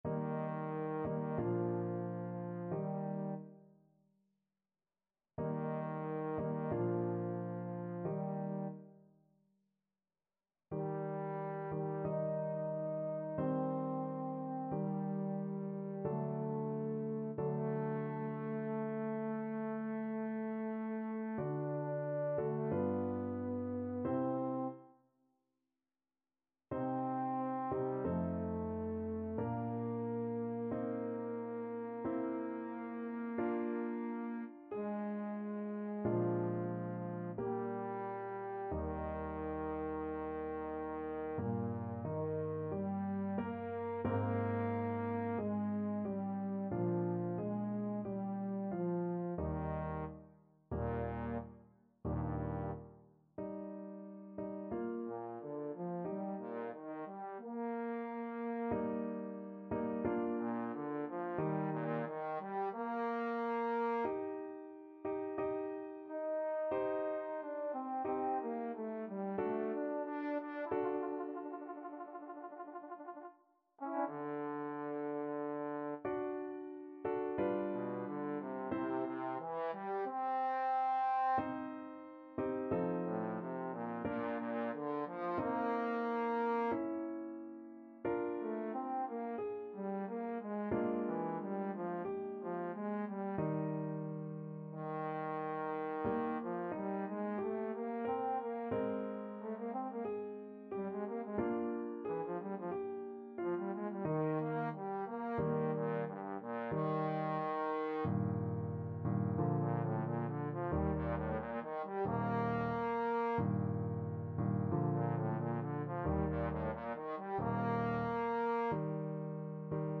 Classical Beethoven, Ludwig van Violin Concerto, Op. 61, Second Movement Trombone version
Trombone
Eb major (Sounding Pitch) (View more Eb major Music for Trombone )
Larghetto =c.45
4/4 (View more 4/4 Music)
Classical (View more Classical Trombone Music)
beethoven_violin_concerto_2nd_TBNE.mp3